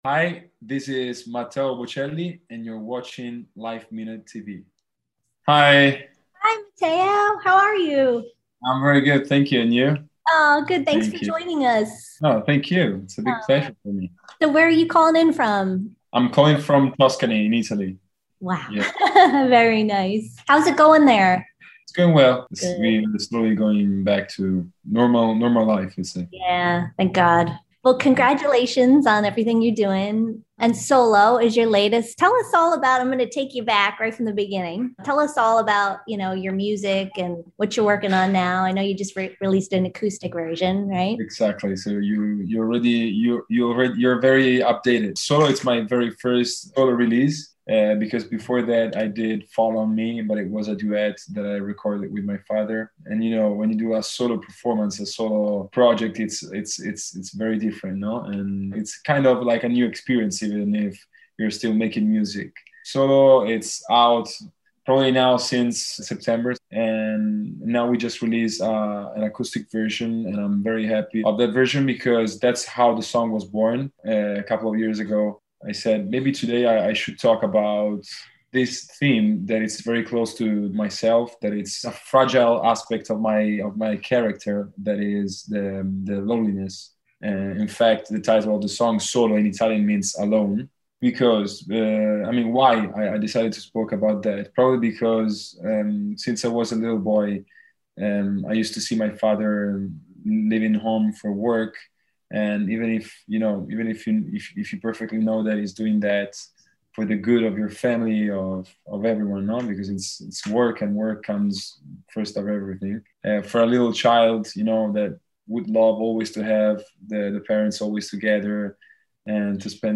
Despite growing up around music, and his legendary tenor father, he kept his vocal talents a secret until he was sixteen. We caught up with him recently from his home in Tuscany to hear all about it, including his debut single, "Solo," and what he’s working on next.